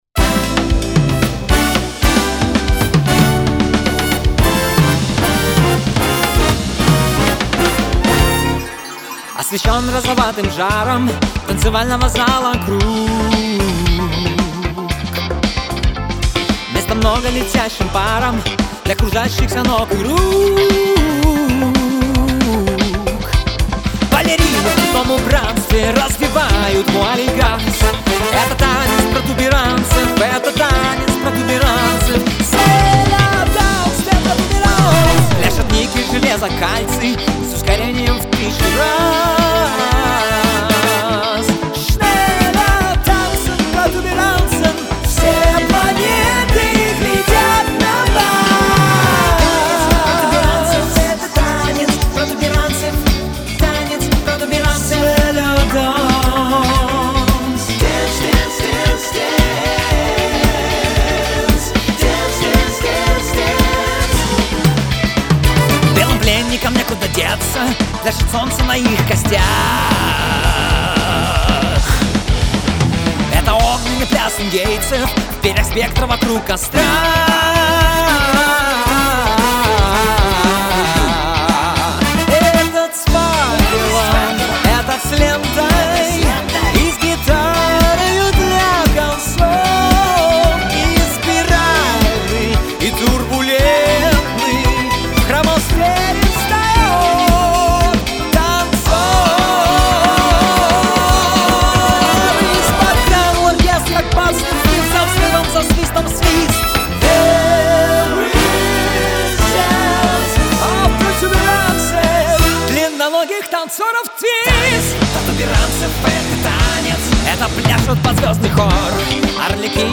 По моему при баунсе балансы громкостей слетели?
Вот свёл как мог, через не хочу.